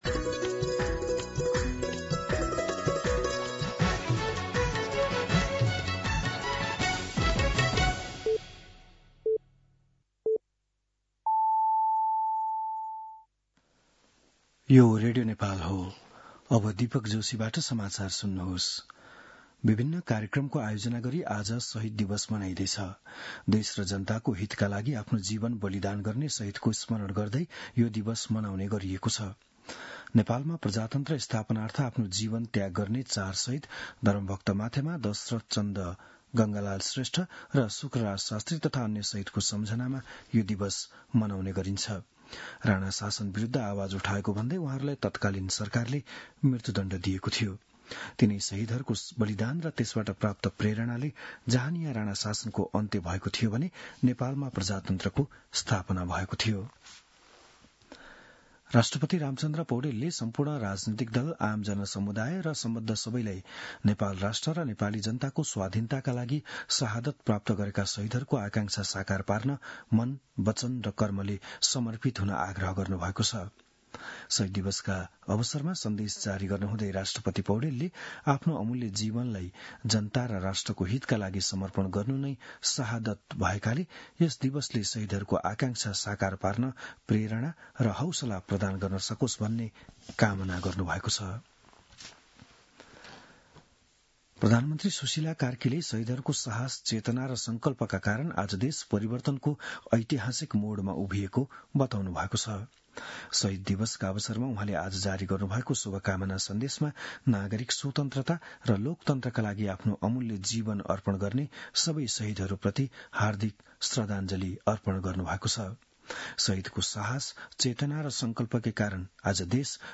बिहान ११ बजेको नेपाली समाचार : १६ माघ , २०८२